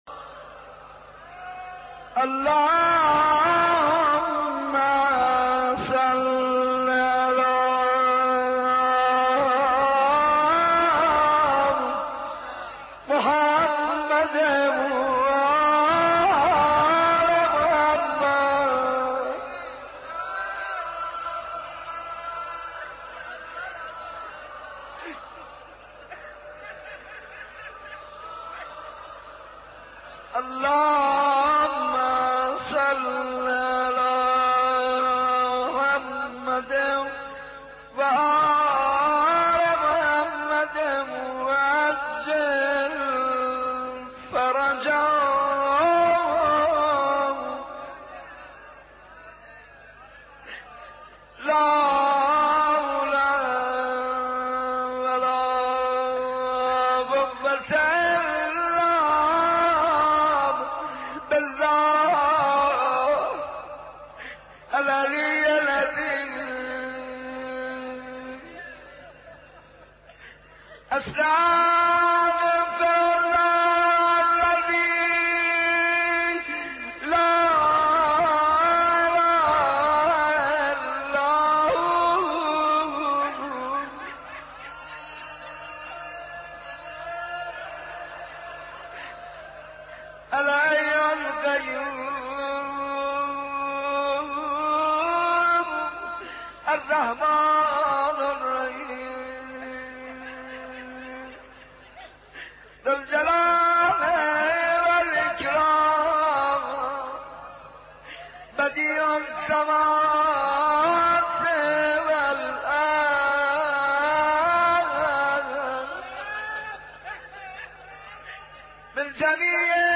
شب احیا مسجد ارگ